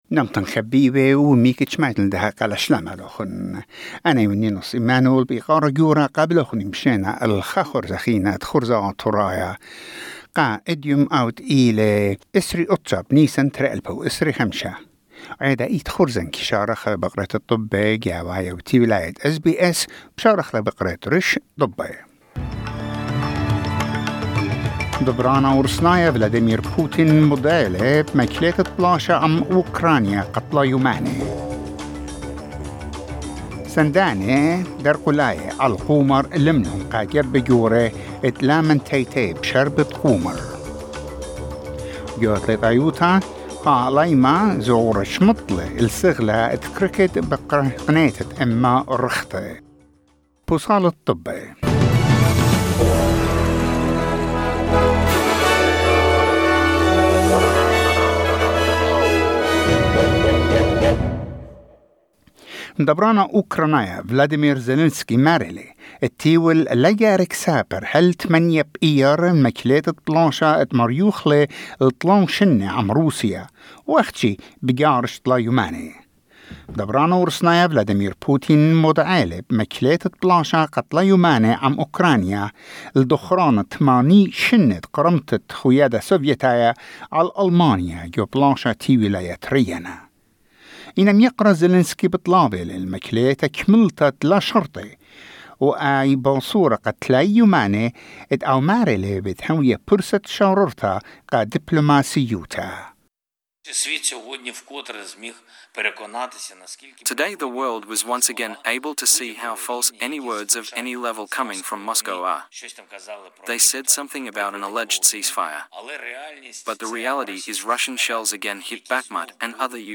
SBS Assyrian news bulletin: 29 April 2025